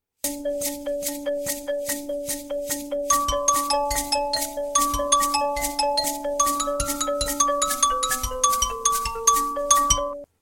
Others sound terrible and even feature some wrong notes.